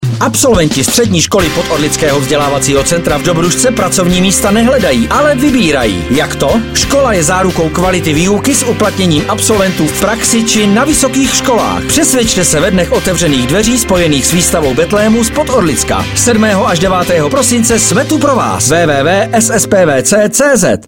Reklamní spot